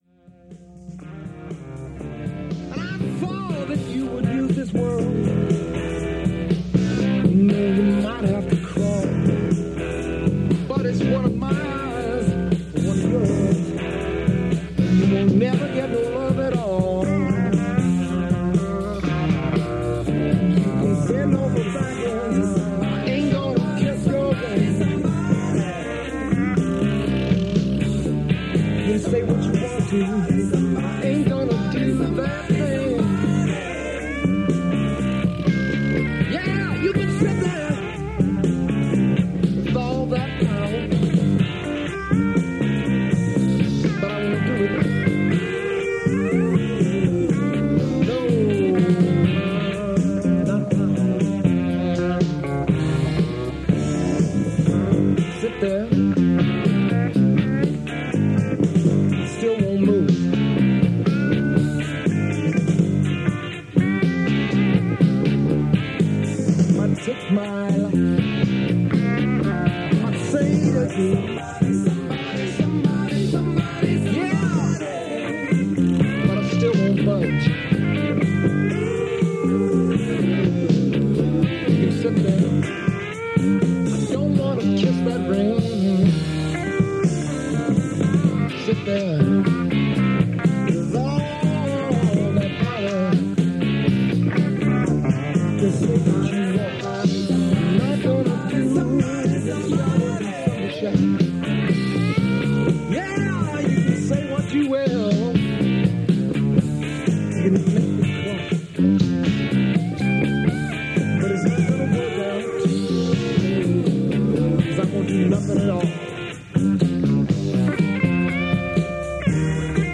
And even the radio is playing bands you’ve never heard of.